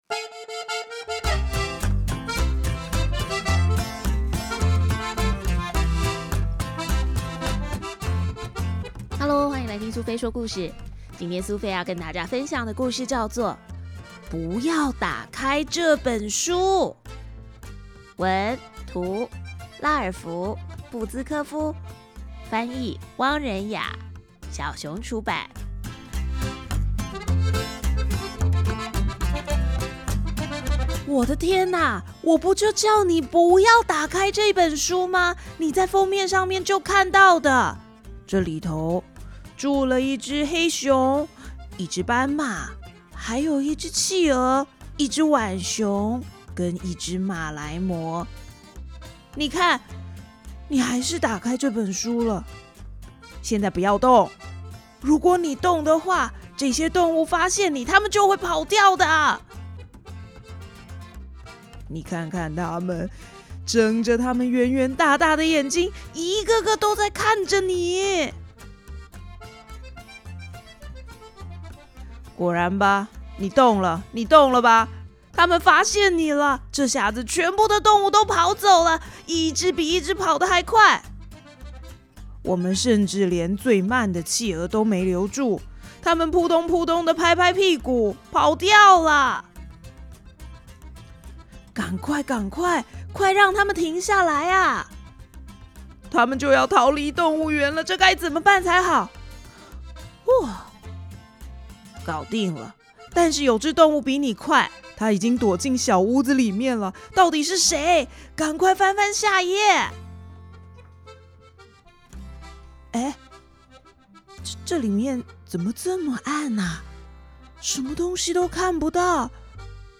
不要打開這本書（配音）.mp3